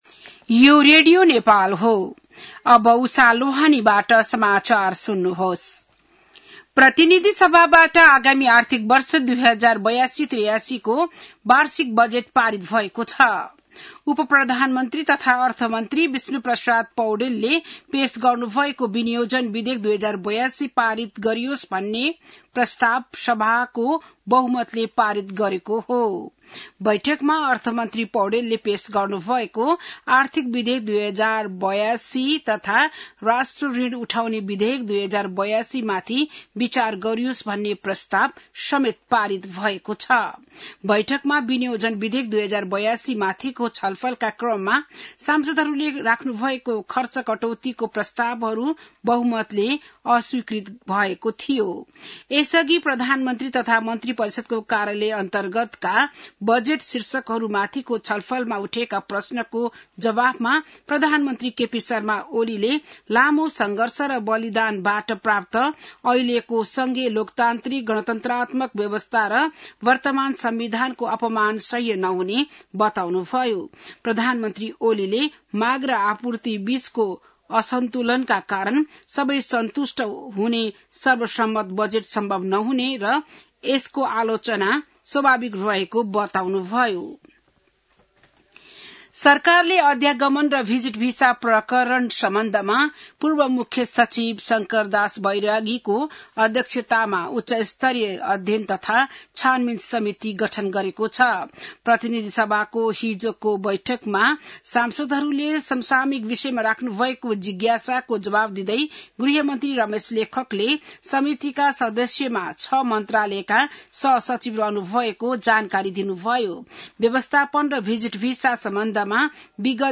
बिहान १० बजेको नेपाली समाचार : ११ असार , २०८२